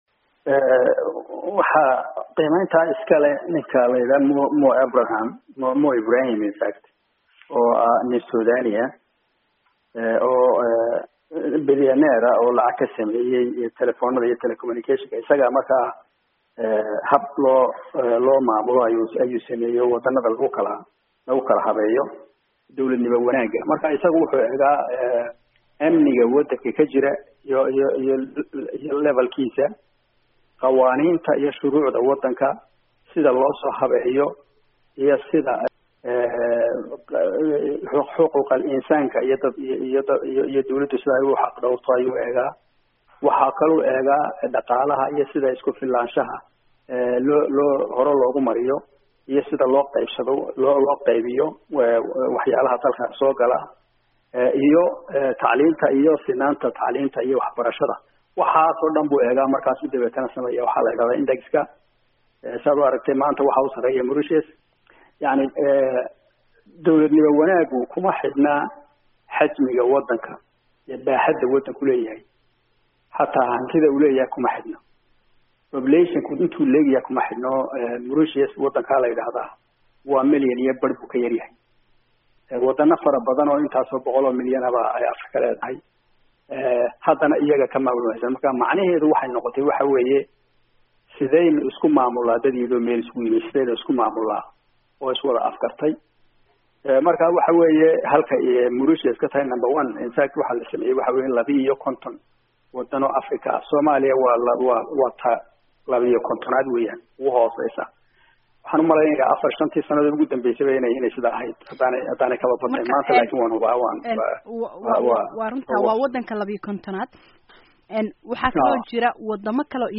ayaa wareysatay.